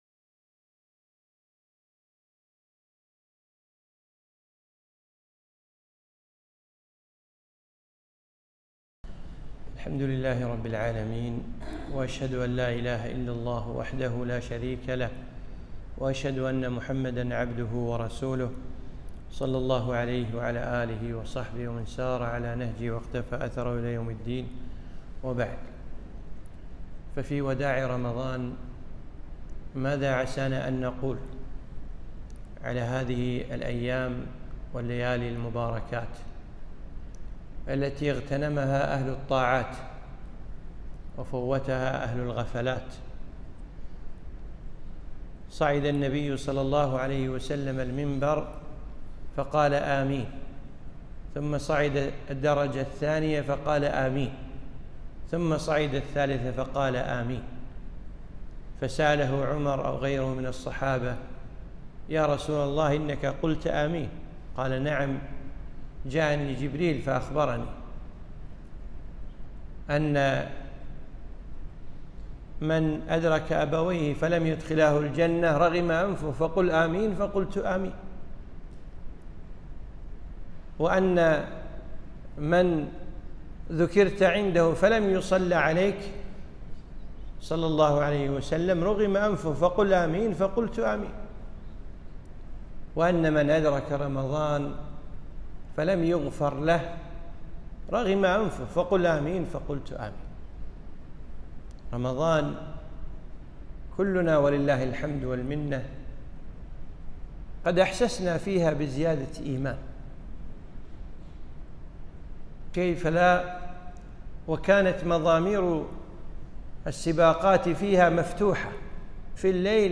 محاضرة - وداع رمضان